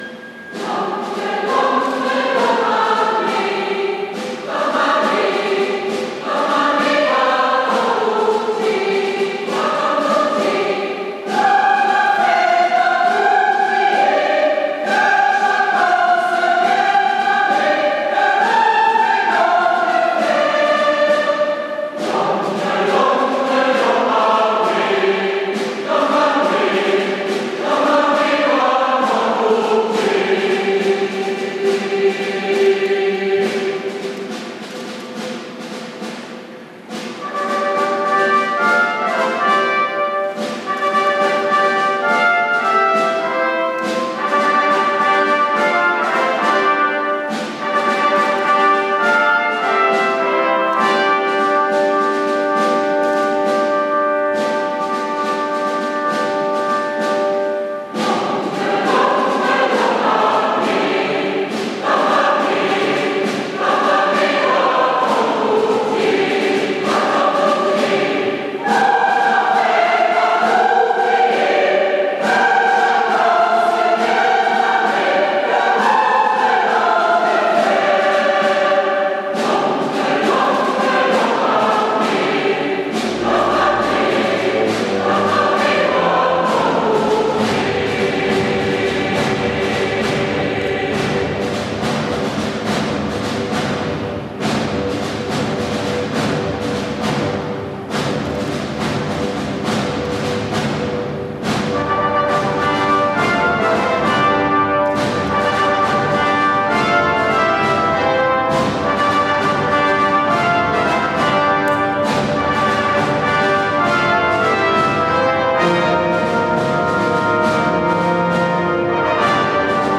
Wir sind über 60 aktive Sängerinnen und Sänger, die sich jeden Montag um 20.00 Uhr im Pfarrzentrum St. Johannes in Salzkotten treffen.